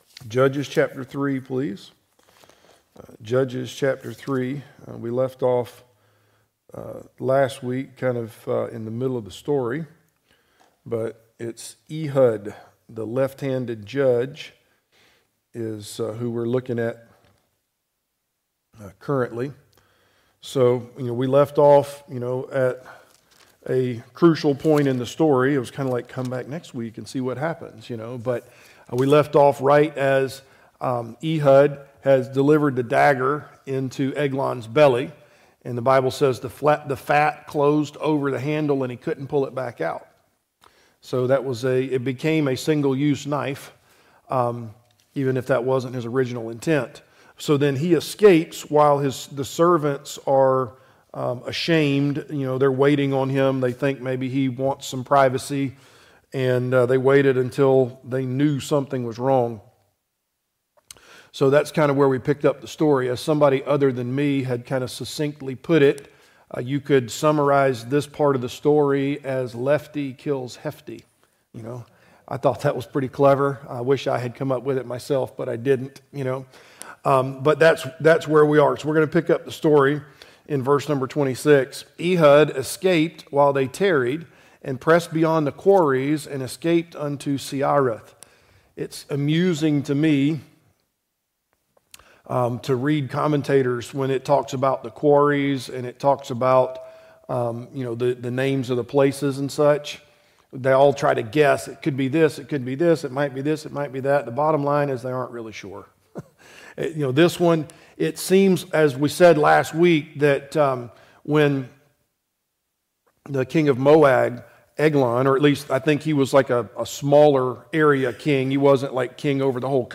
Passage: Judges 4 Service Type: Adult Sunday School Class « Marks of a Model Church pt. 3 What Will You Do with the Gospel?